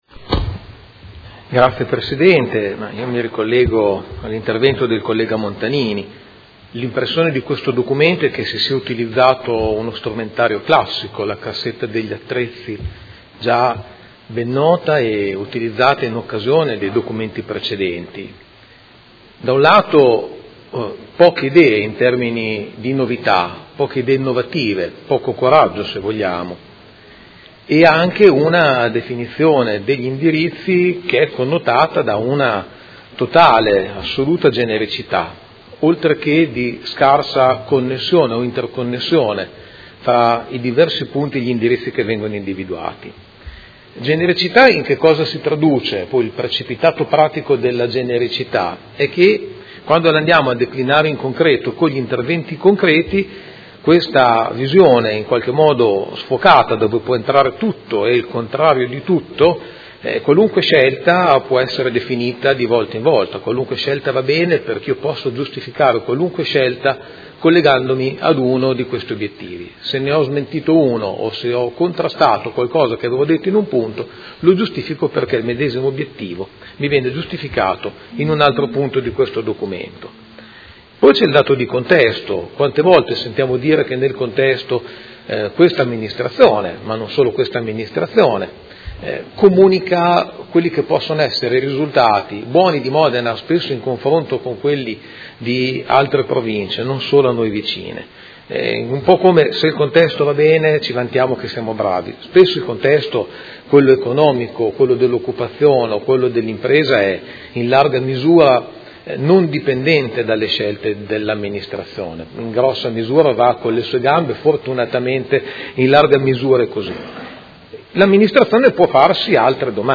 Seduta del 27/09/2018. Dibattito su proposta di deliberazione: Documento Unico di Programmazione 2019-2021 – Approvazione